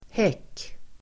Uttal: [hek:]